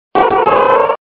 Archivo:Grito de Illumise.ogg